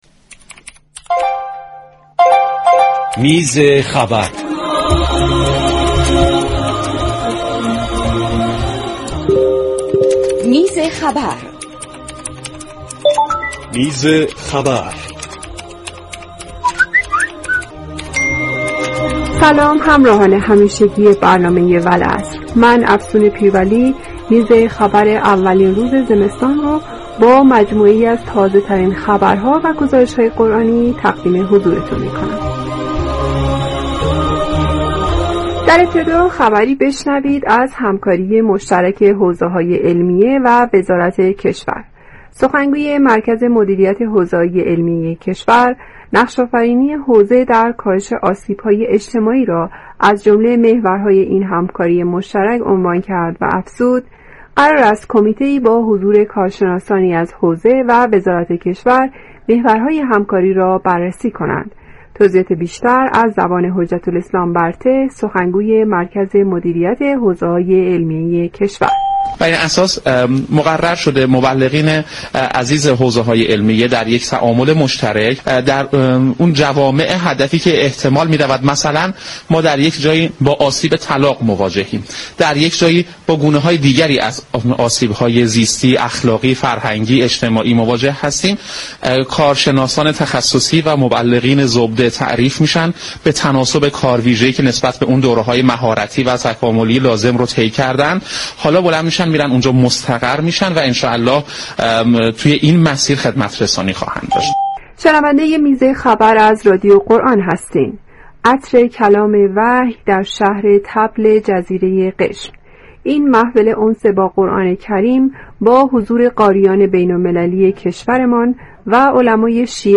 عناوین اخبار قرآنی مطرح شده در بخش میز خبر برنامه والعصر